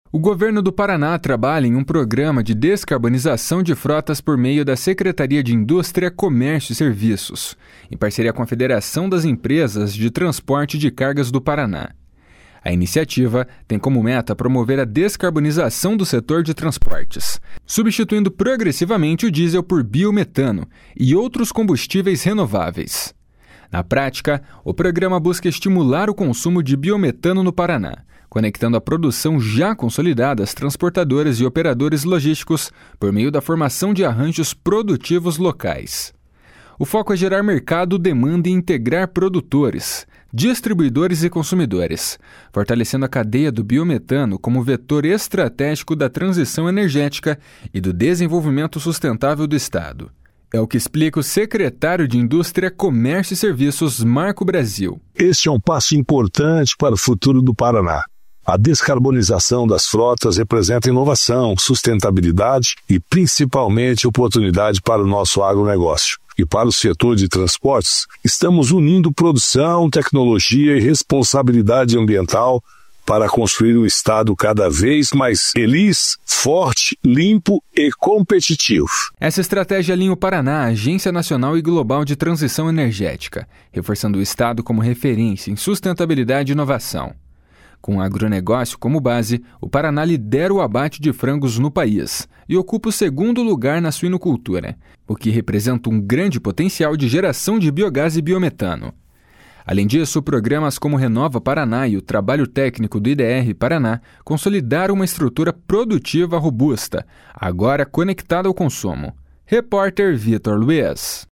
É o que explica o secretário de Indústria, Comércio e Serviços, Marco Brasil. // SONORA MARCO BRASIL //